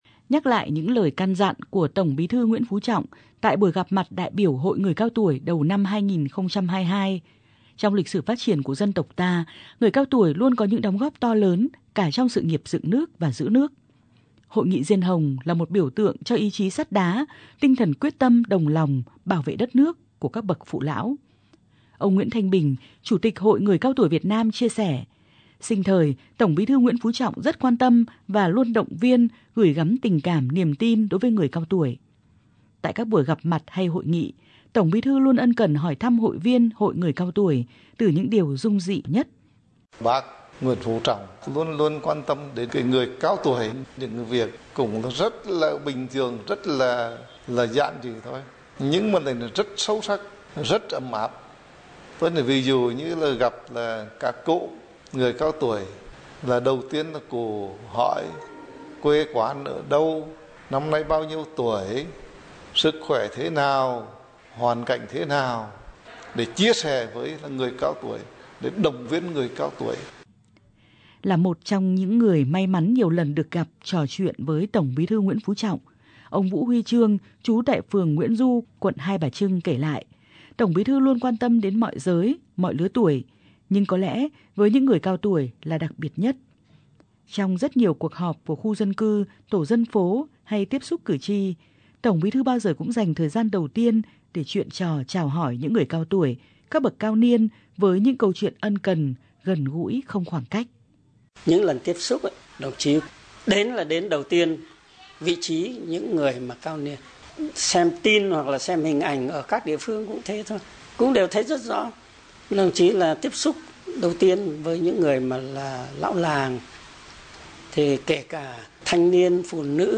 THỜI SỰ Tin thời sự